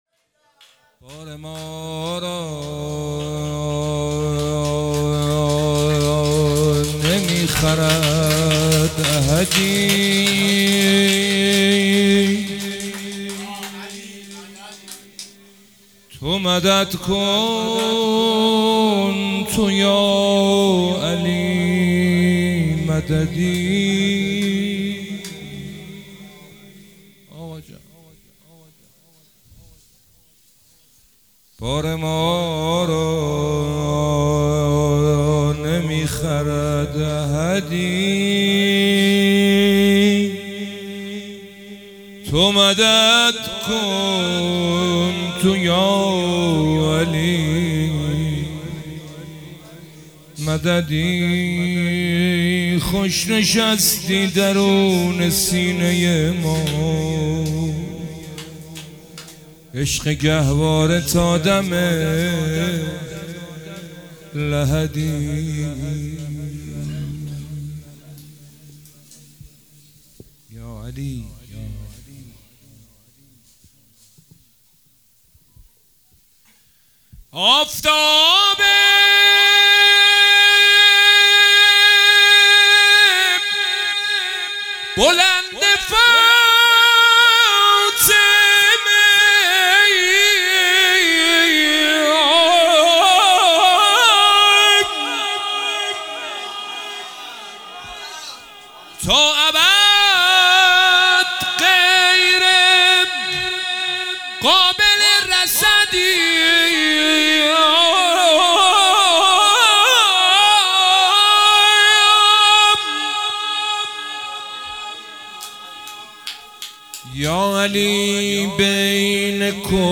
مدح حضرت امیرالمومنین امام علی (ع)